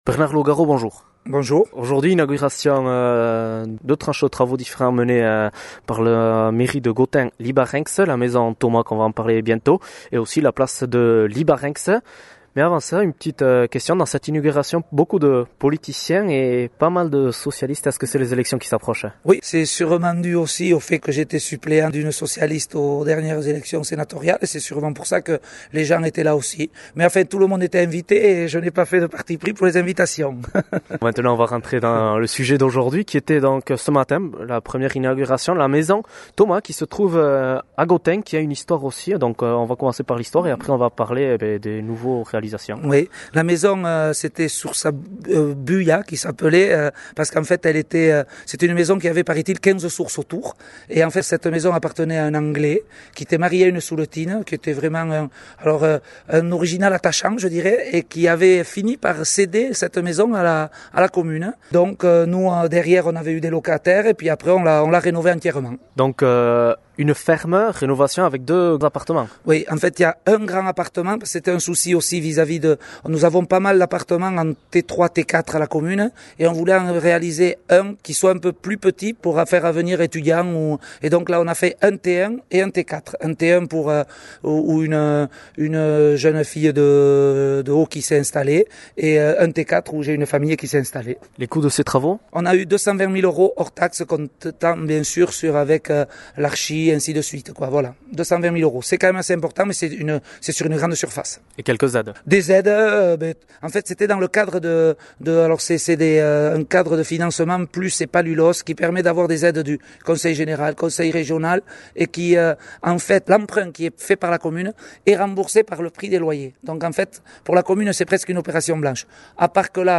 Entzün Bernard Lougarot Gotaineko aüzapeza :